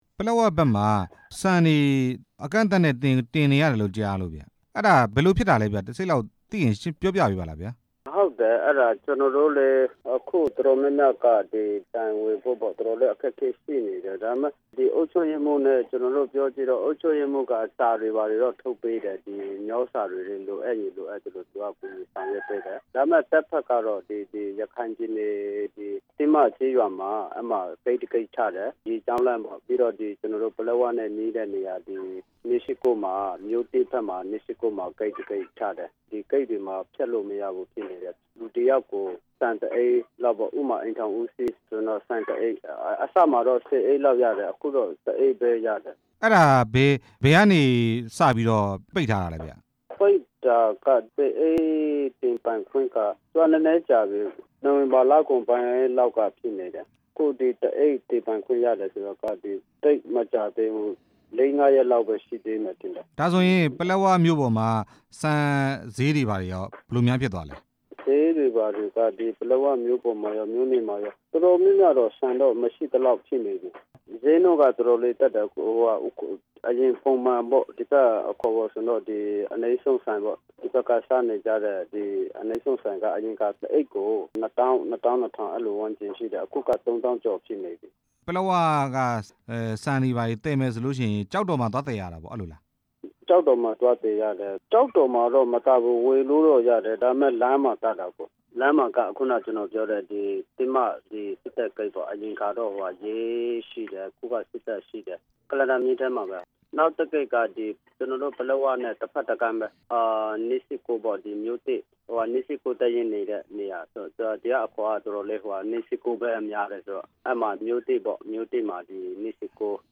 ပလက်ဝမှာ ဆန် သယ်ဆောင်ခွင့် ကန့်သတ်ထားတဲ့ အကြောင်း ဆက်သွယ် မေးမြန်းချက်